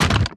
drop_light.ogg